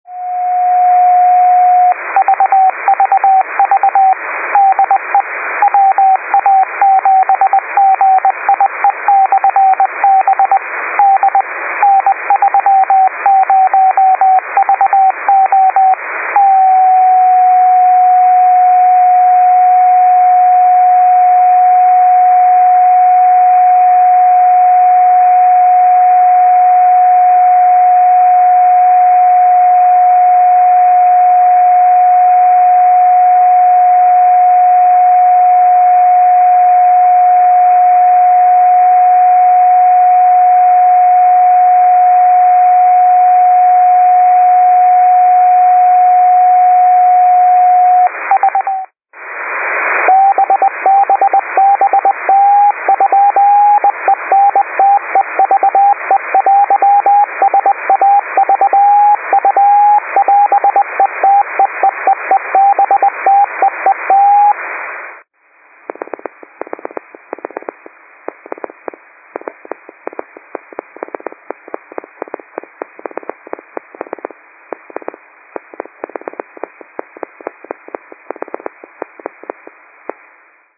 Keying is actually FSK-CW, with the "Key-Up" frequency being about 10 kHz higher than the "key down" frequency:  This means that the transmitter is keyed continuously - a fact to remember if attempting to receive it using a wider IF (e.g. narrow or wideband FM.)
Back then, the keying ws somewhat chirpy and the frequency tends to drift a few kHz with temperature.
The first portion of this recording contains an entire beacon cycle while the second portion is the "unkeyed" frequency (about 10 kHz up) with the "reversed" keying.  The final portion was recorded in Narrow FM mode tuned midway between the two frequencies:  The "clicking" is the shifting of the beacon frequency during keying.
This recording was made with the transverter, using a 17dbi horn, sitting in my living room:  The signal had to go through walls, trees, and a neighbor's house.